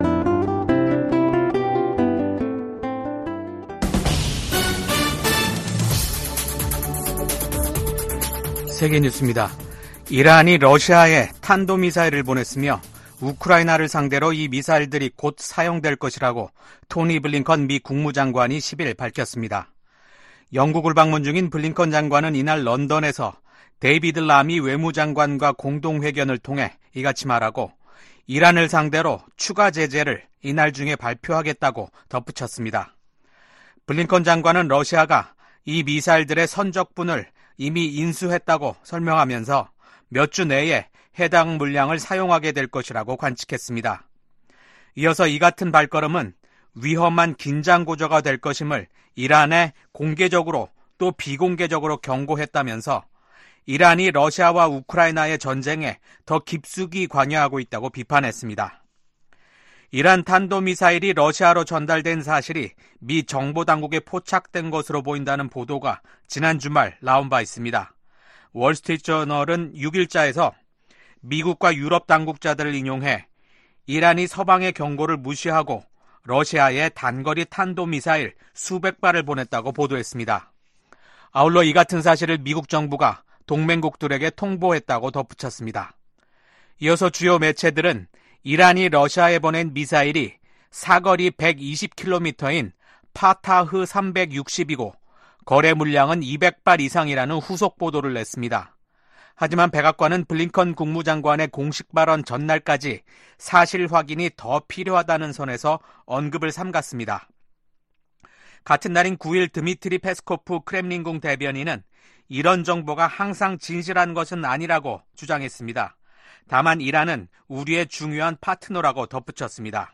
VOA 한국어 아침 뉴스 프로그램 '워싱턴 뉴스 광장' 2024년 9월 11일 방송입니다. 민주당 대통령 후보인 카멀라 해리스 부통령이 당선되면 동맹을 강화하며 국제 지도력을 발휘할 것이라는 입장을 재확인했습니다. 북한 사립대학 외국인 교수진의 복귀 소식에 미국 국무부는 미국인의 ‘북한 여행 금지’ 규정을 상기했습니다. 김정은 북한 국무위원장이 9.9절을 맞아 미국의 핵 위협을 주장하며 자신들의 핵 무력을 한계 없이 늘려나가겠다고 밝혔습니다.